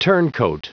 Prononciation du mot turncoat en anglais (fichier audio)
Prononciation du mot : turncoat